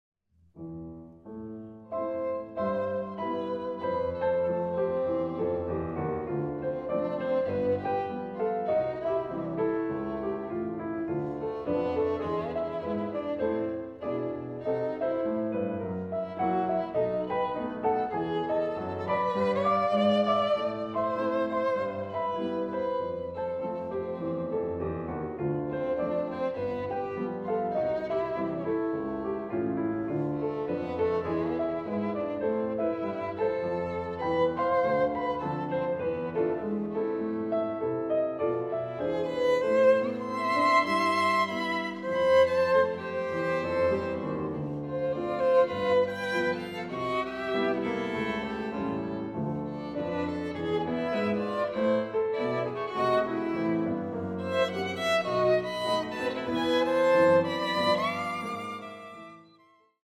Violine
Klavier